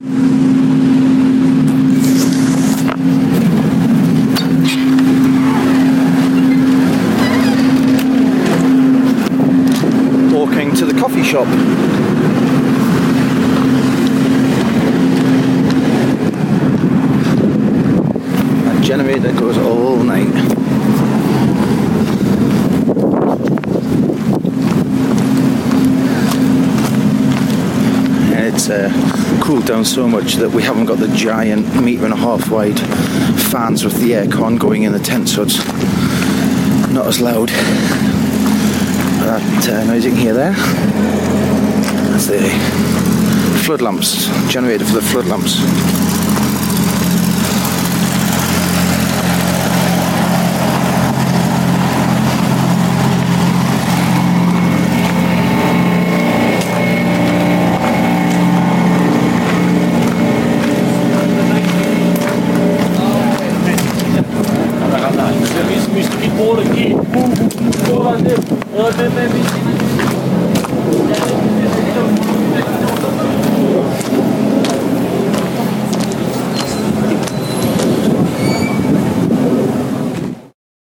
Walking to the coffee shop [soundscape]